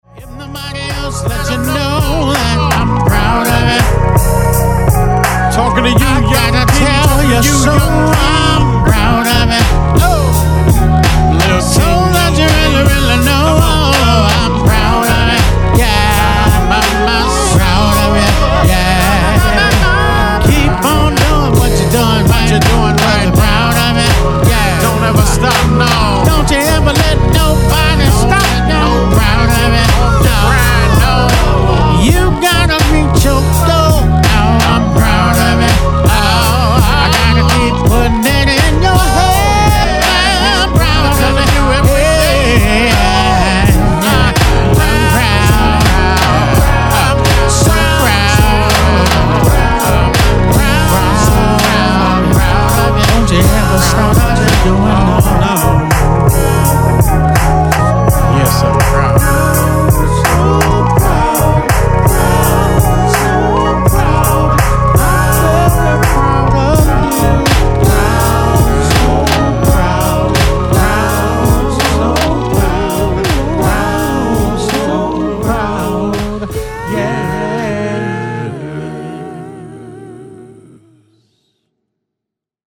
INSPIRATIONAL R&B